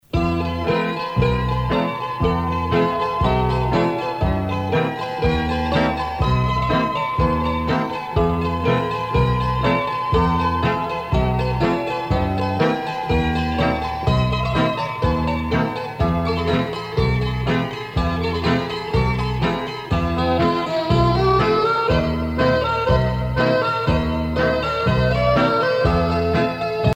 danse : sirtaki
Pièce musicale éditée